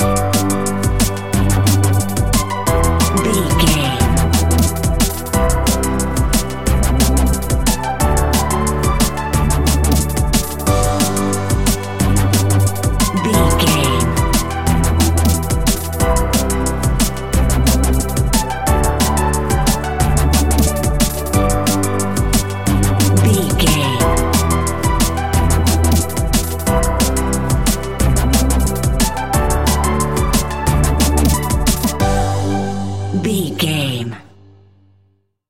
Ionian/Major
Fast
uplifting
lively
futuristic
hypnotic
industrial
frantic
drum machine
synthesiser
electronic
sub bass
synth leads
synth bass